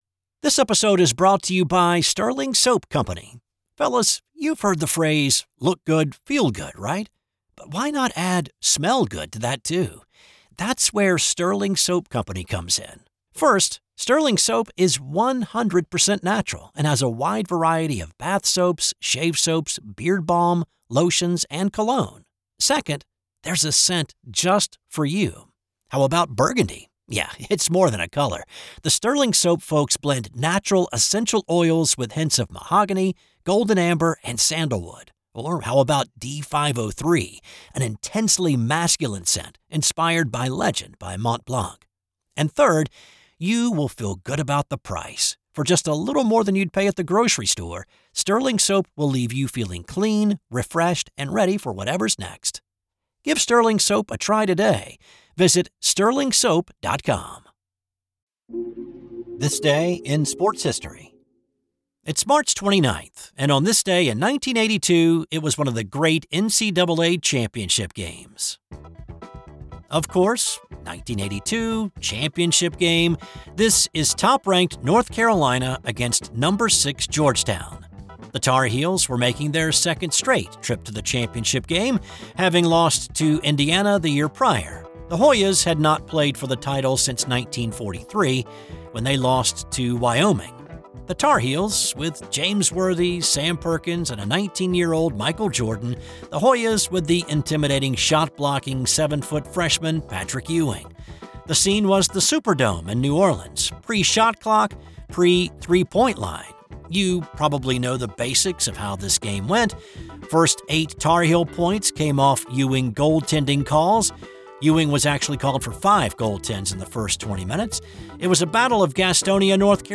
'This Day in Sports History' is a one PERSON operation. I research, write, voice, and produce each show.